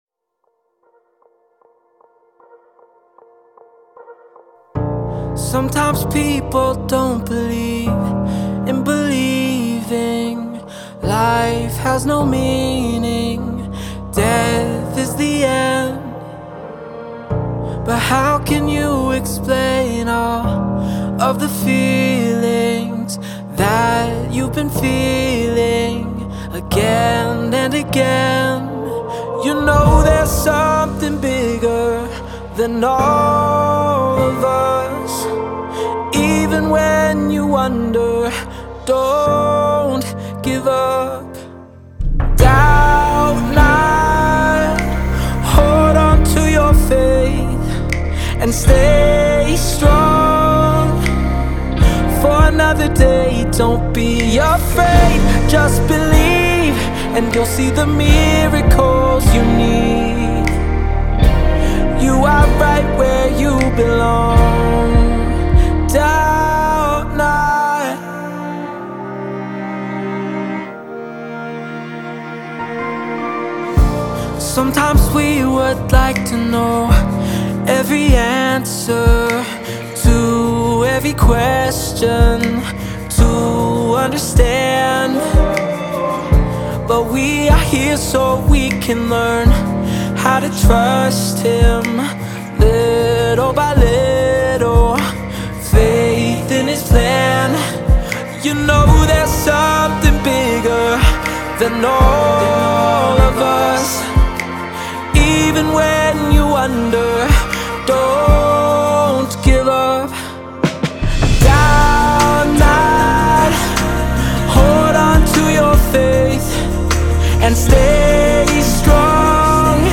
Sacred Music